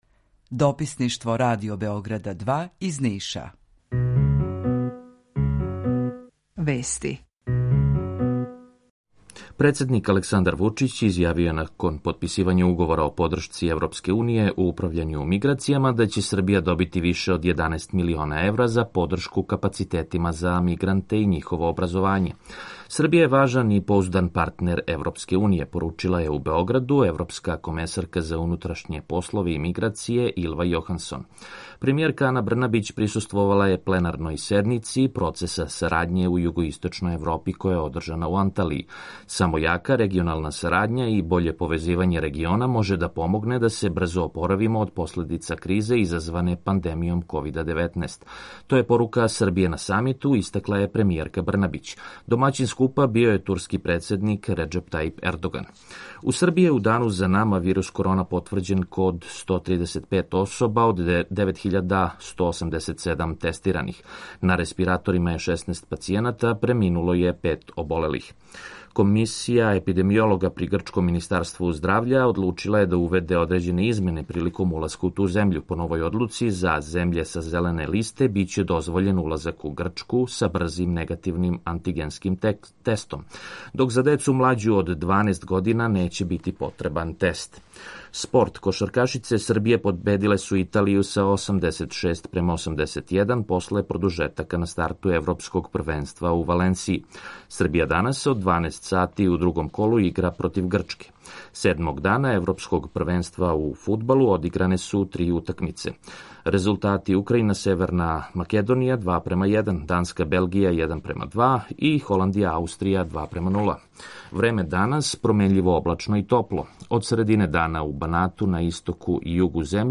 Укључење Бањалуке
Јутарњи програм из три студија
У два сата, ту је и добра музика, другачија у односу на остале радио-станице.